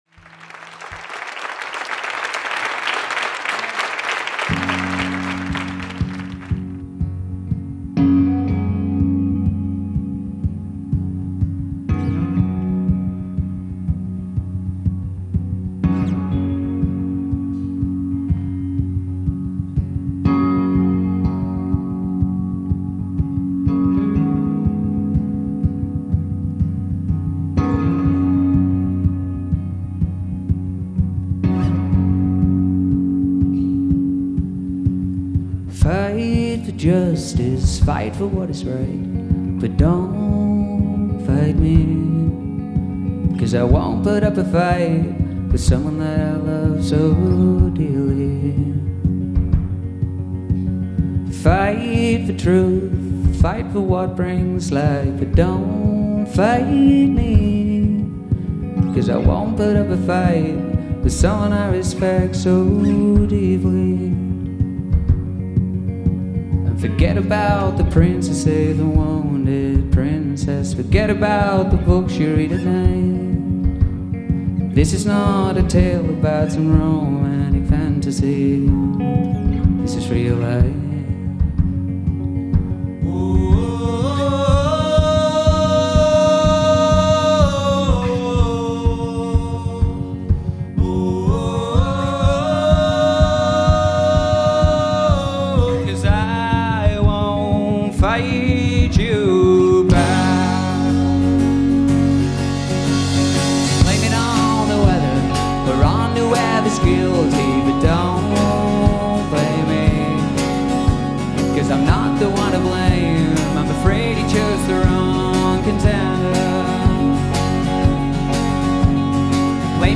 Folk-Rock